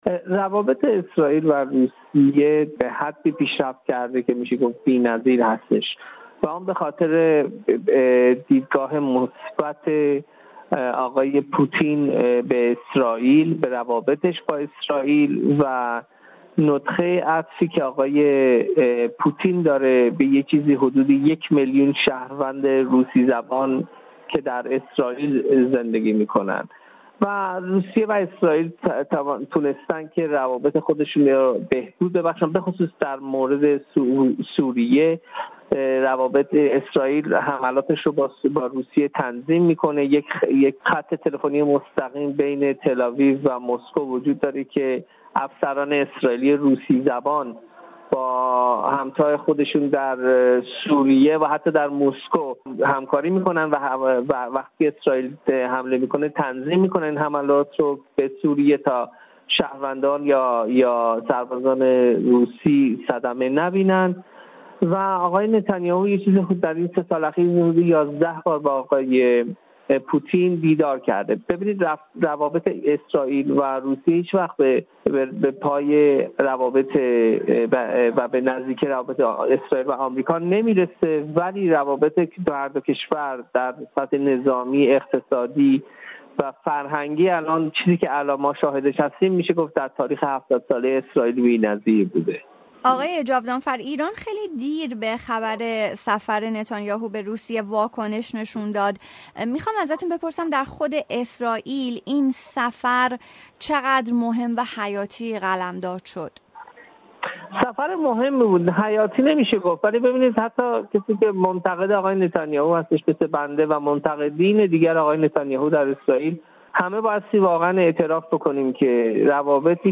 در گفت‌وگو با رادیوفردا